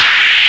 Amiga 8-bit Sampled Voice
scrape.mp3